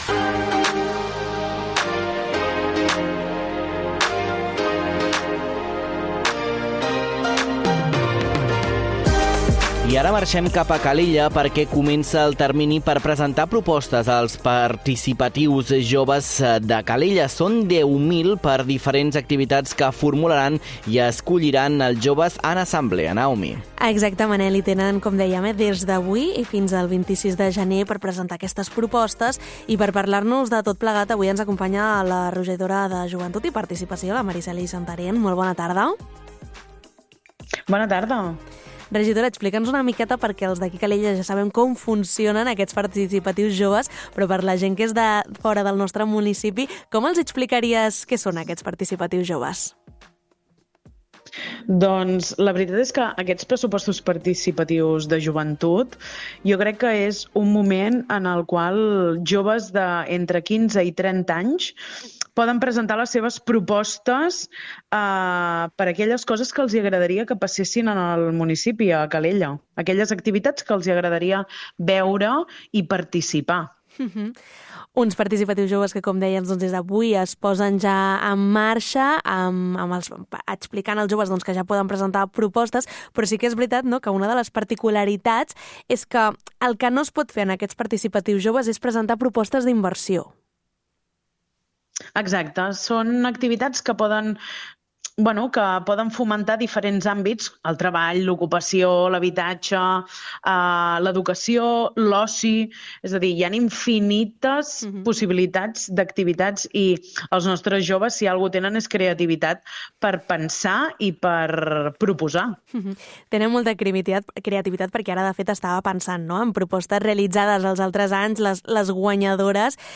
Avui passat per l’Ona Maresme, la regidora de Joventut, Mariceli Santaren, per donar-nos tots els detalls.
ENTREVISTA-PARTICIPATIUS.mp3